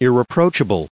added pronounciation and merriam webster audio
1633_irreproachable.ogg